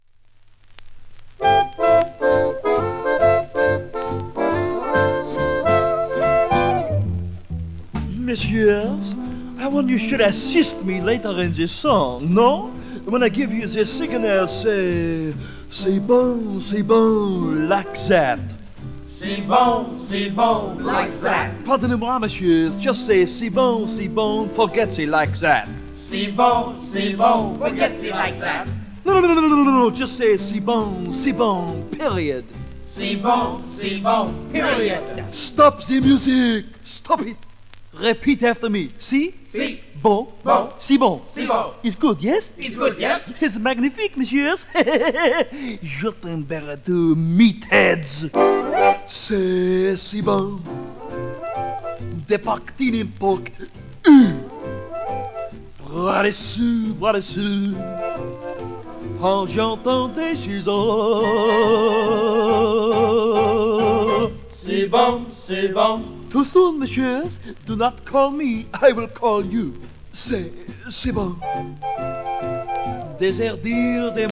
1953年録音